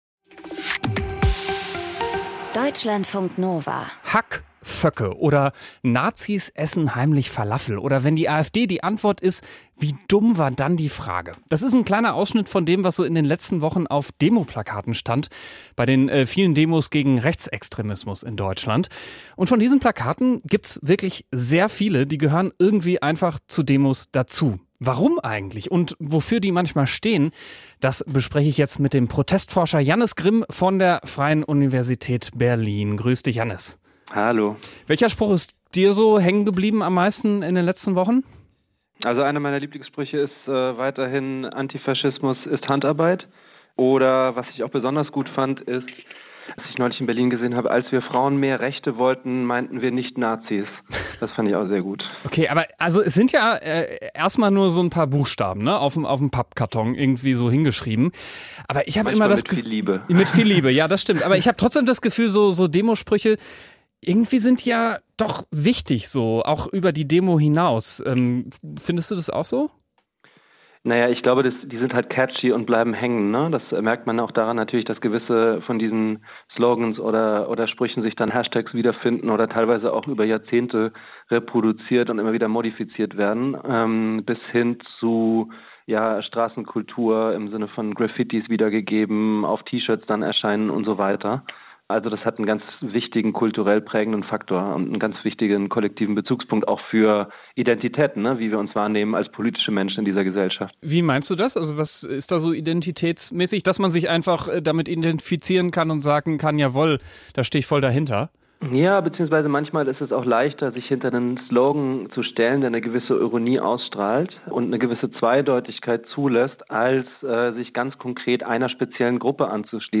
Kommentar: Ein weiterer Abbau des Asylrechts verbietet sich